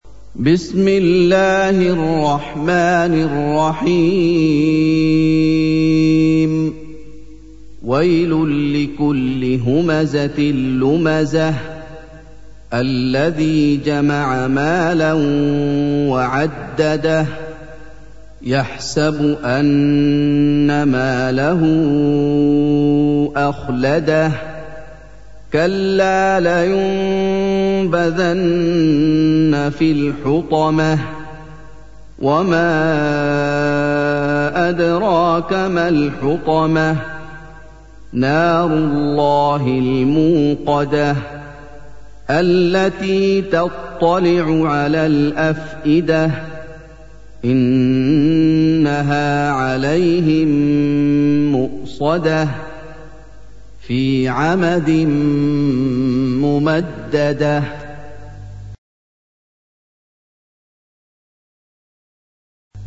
استمع أو حمل سُورَةُ الهُمَزَةِ بصوت الشيخ محمد ايوب بجودة عالية MP3.
سُورَةُ الهُمَزَةِ بصوت الشيخ محمد ايوب